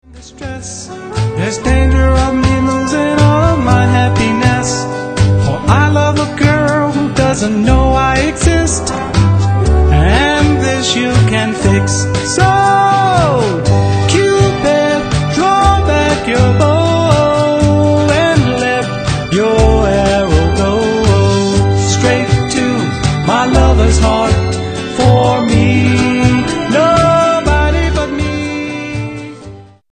fruity jazz cover